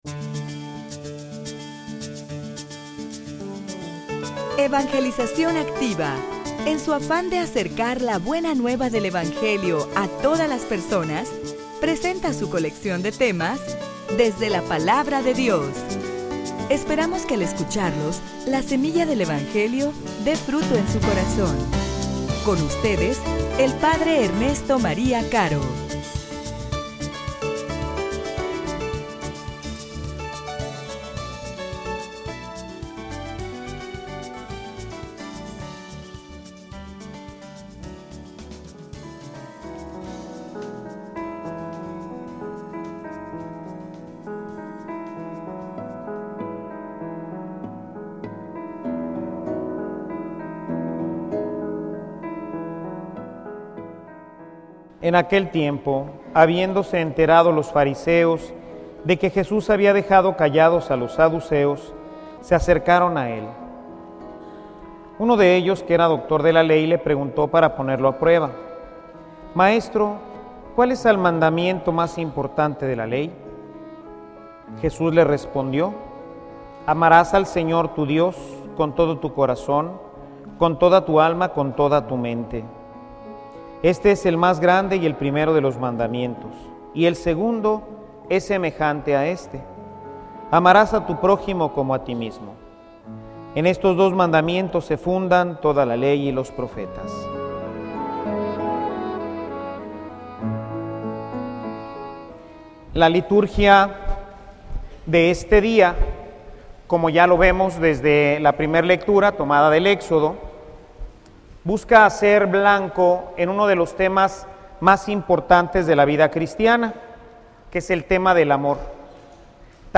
homilia_Ama_a_tu_projimo_al_mas_proximo.mp3